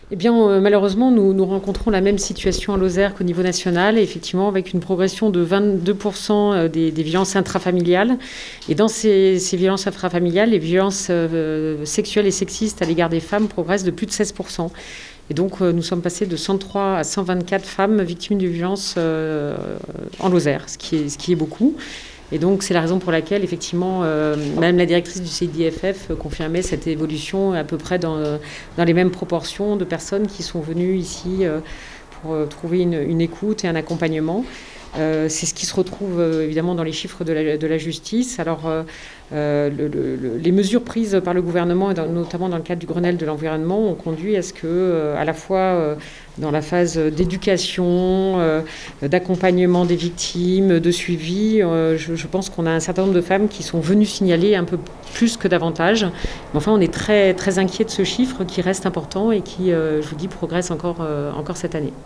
Valérie Hastch, préfète.